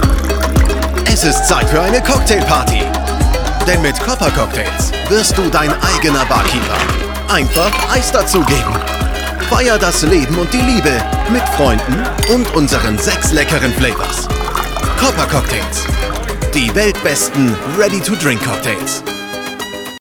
Native Voice Samples
Commercial Demo
TLM 103 + Sennheiser MKH416
BaritoneBass